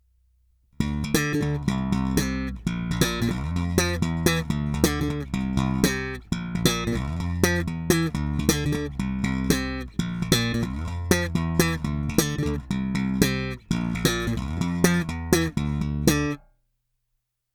Slap v korekcemi na středu
Slap s basy naplno a výškami na středu